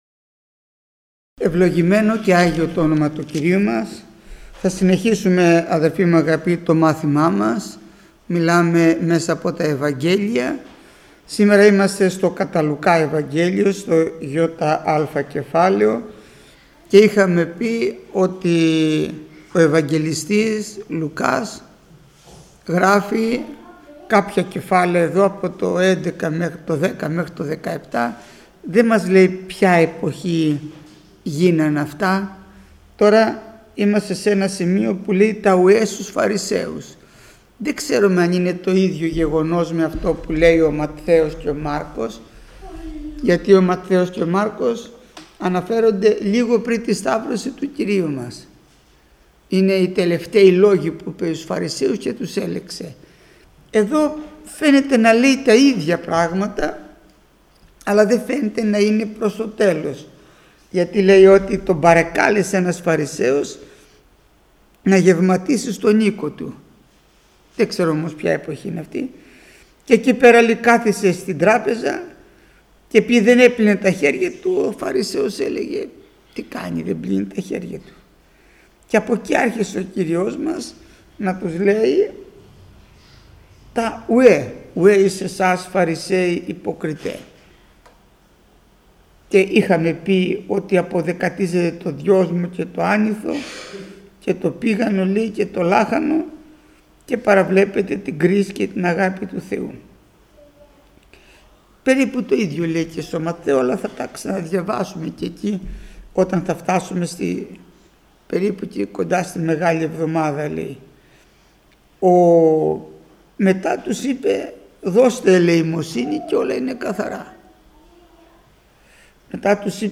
Μάθημα 477ο Γεννηθήτω το θέλημά σου